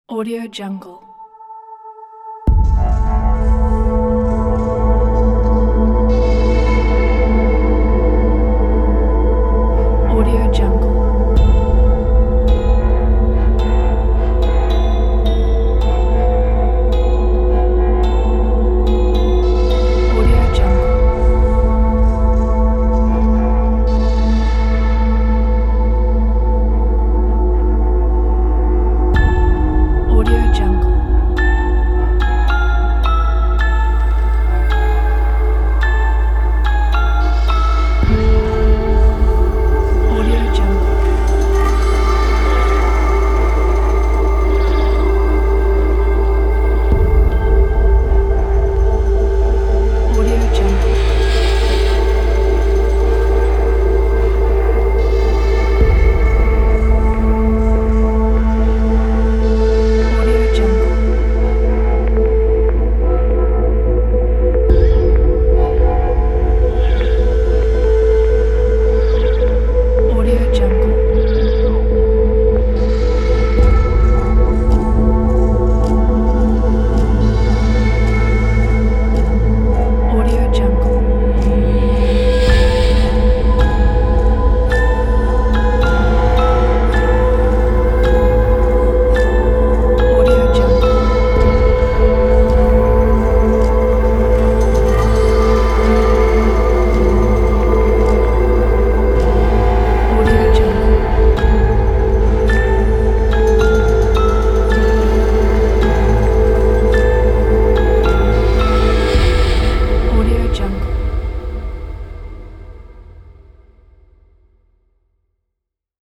آمبیانس و آرام